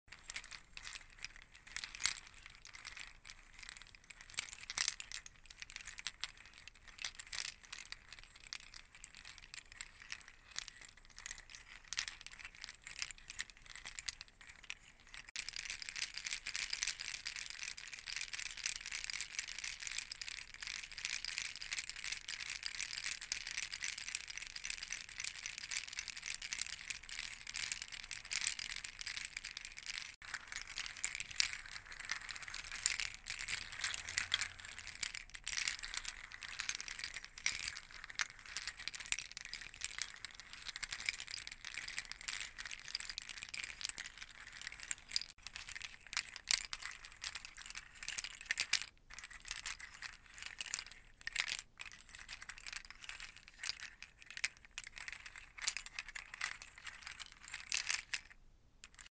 Satisfying Pala Shaker ASMR Sound Effects Free Download